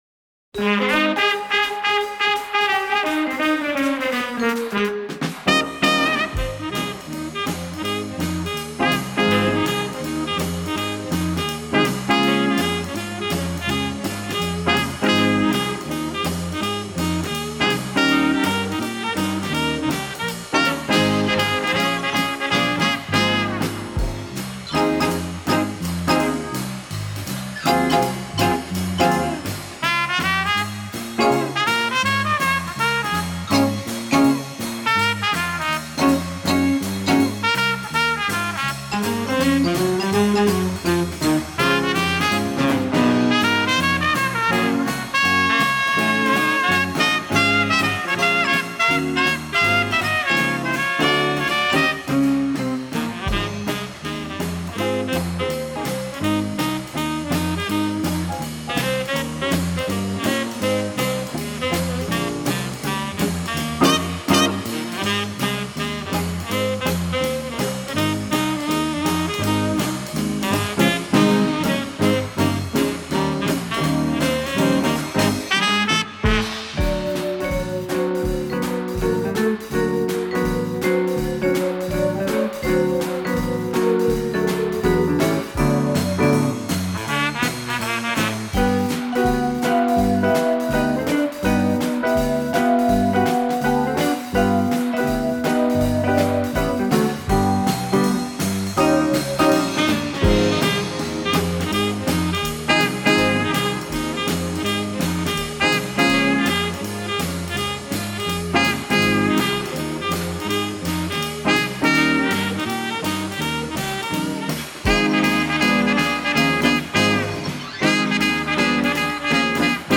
• ノリが跳ねるような感じ: 「バウンス」と呼ばれる、軽快で跳ねるようなリズムが特徴です。